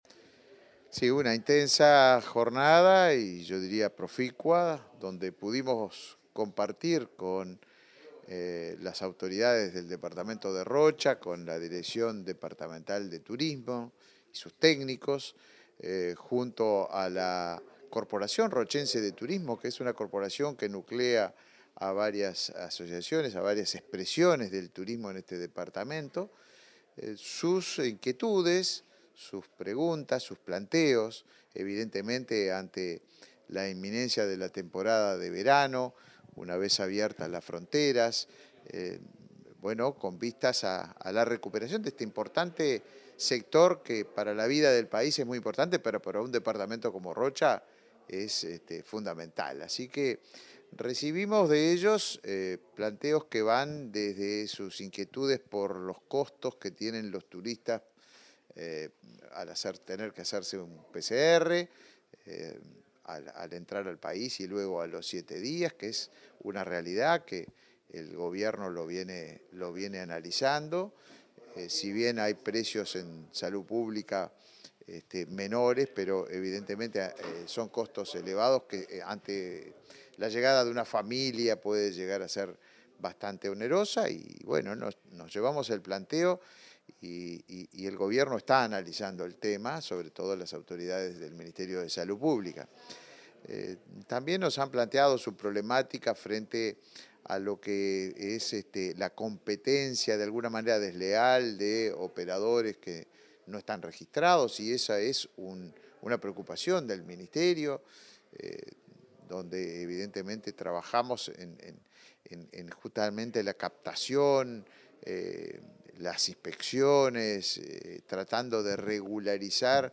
Entrevista al ministro de Turismo, Tabaré Viera